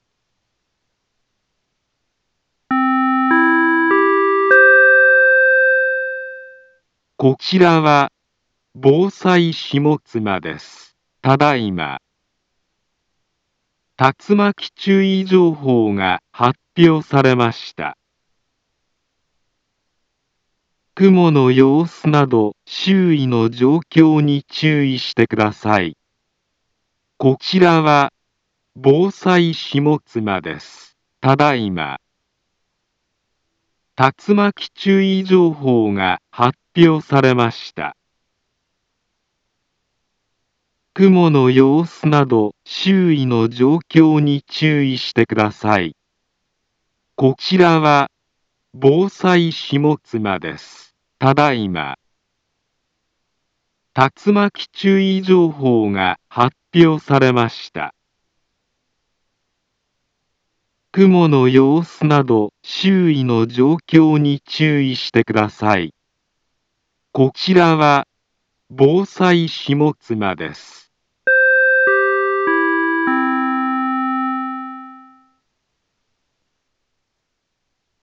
Back Home Ｊアラート情報 音声放送 再生 災害情報 カテゴリ：J-ALERT 登録日時：2024-08-17 18:09:21 インフォメーション：茨城県南部は、竜巻などの激しい突風が発生しやすい気象状況になっています。